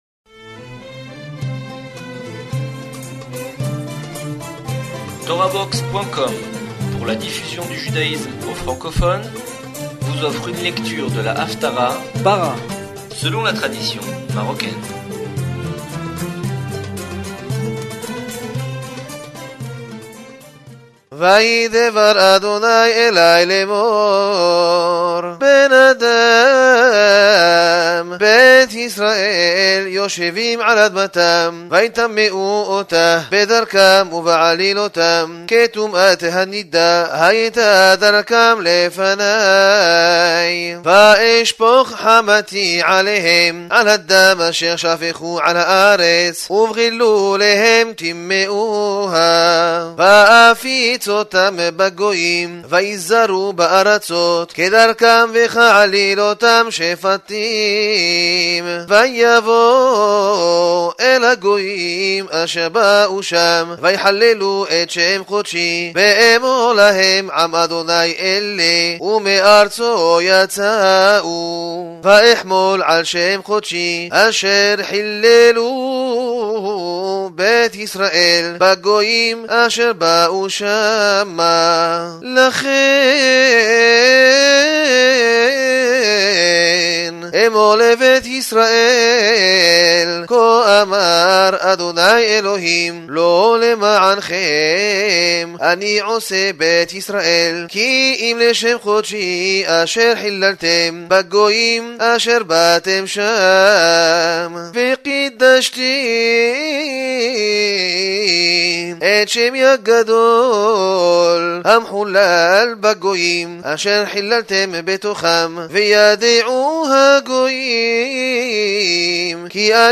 d'après l'air marocain